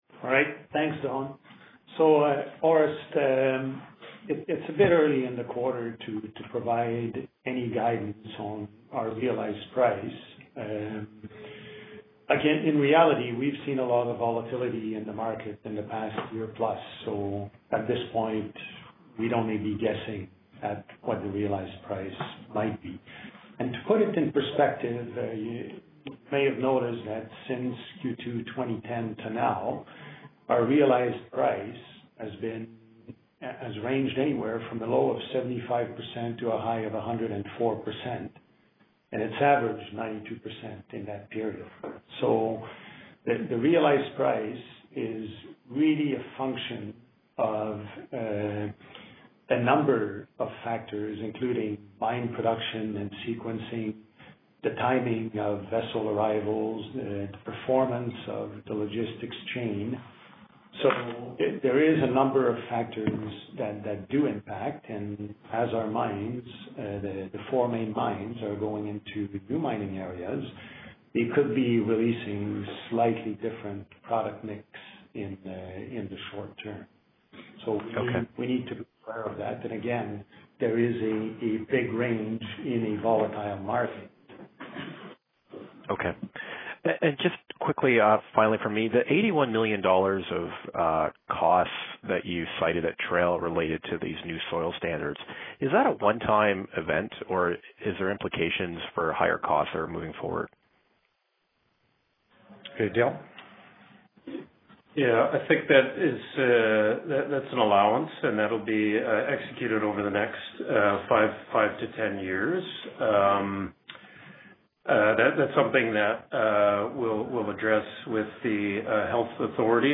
Q4-2017-Conference-Call-Audio-Part-Two.mp3